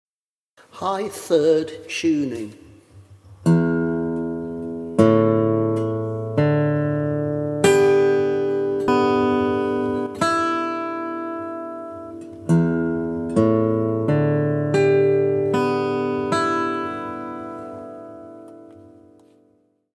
High 3rd guitar stringing/tuning demonstration
4-high-3rd-tuning-2.mp3